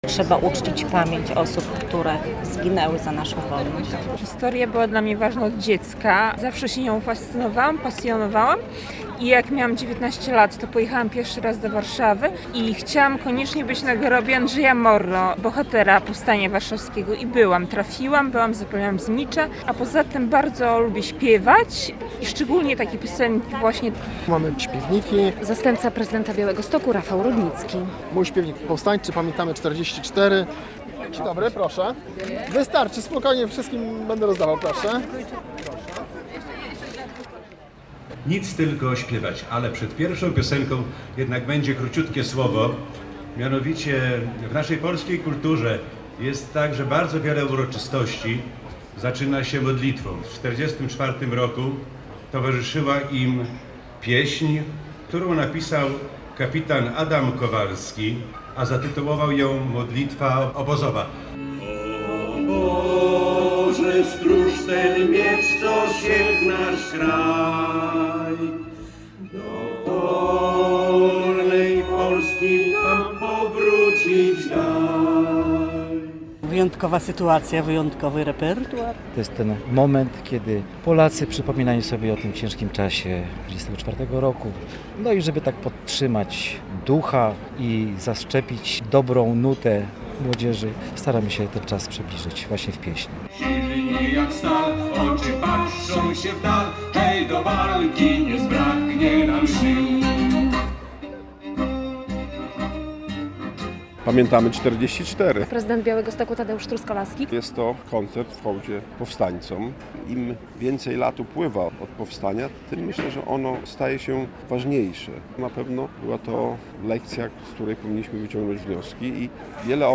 Powstańcze pieśni rozbrzmiewały we wtorek (31.07) w centrum Białegostoku.
Publiczność mogła śpiewać wraz z artystami - były rozdawane śpiewniki z pieśniami powstańczymi.